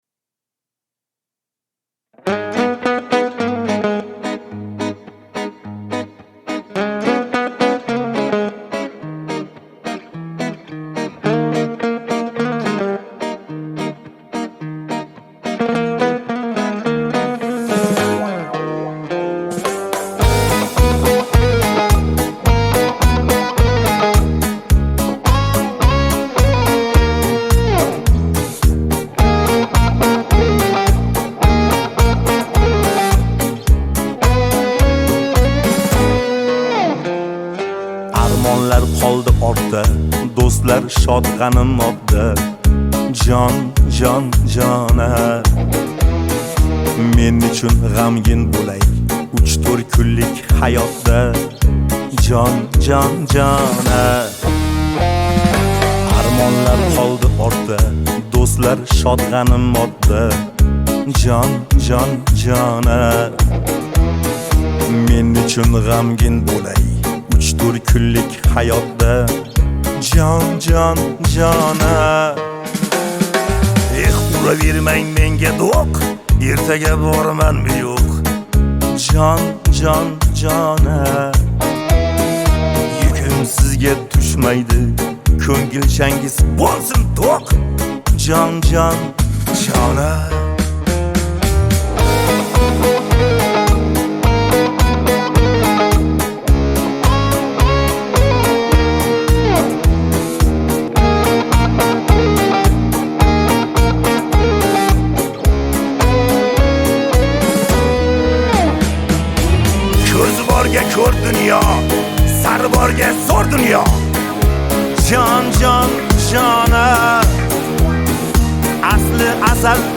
Узбекские песни